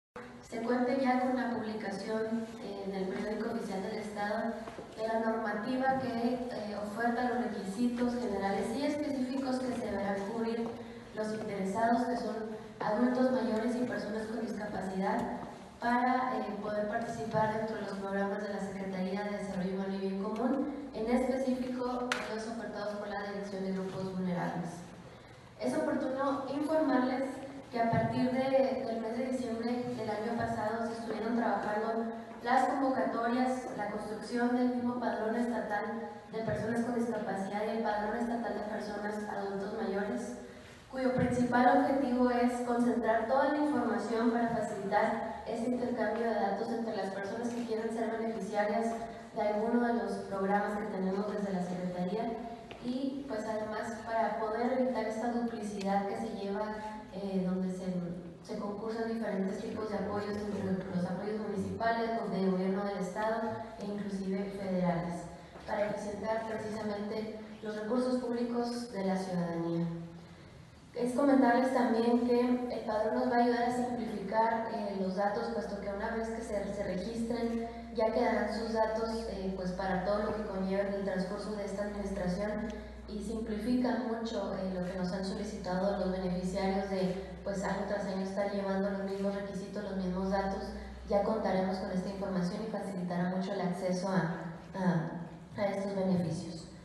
AUDIO: CARLA RIVAS, TITULAR DE LA SECRETARÍA DE DESARROLLO HUMANO Y BIEN COMÚN (SDHyBC)